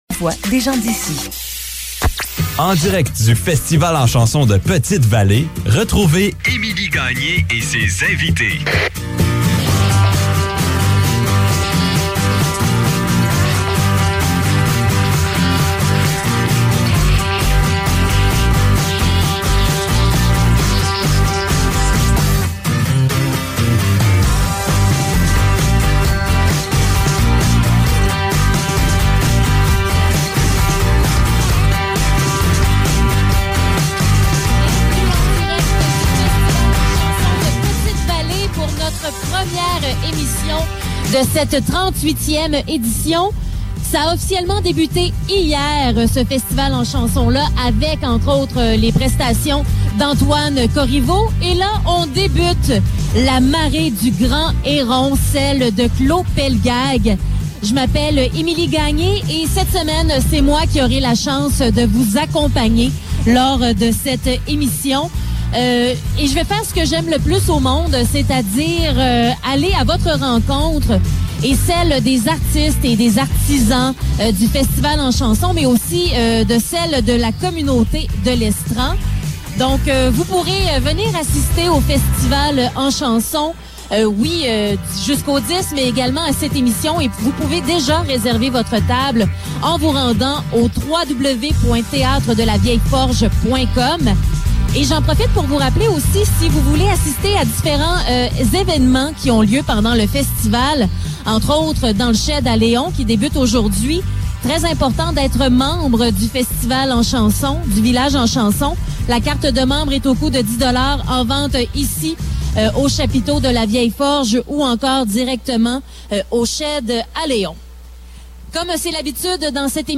C'est parti pour la 38e édition du Festival en chanson de Petite-Vallée.